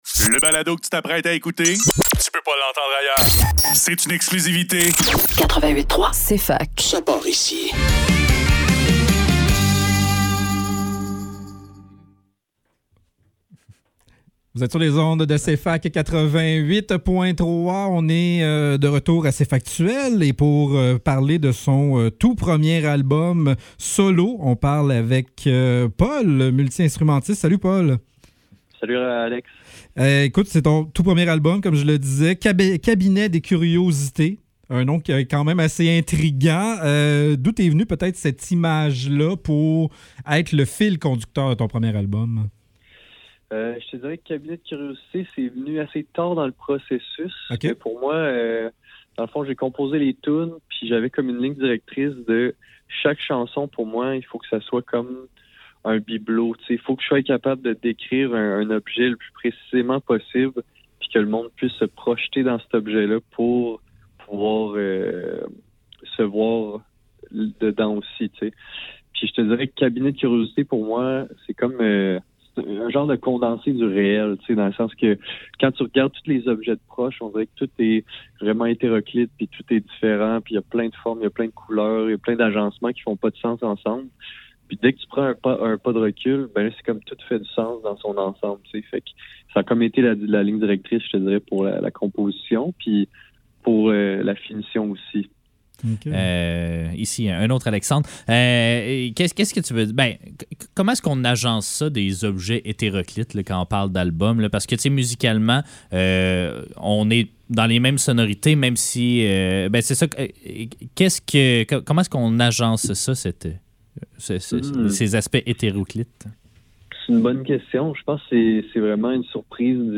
Cfaktuel - Entrevue pol - 12 Mai 2025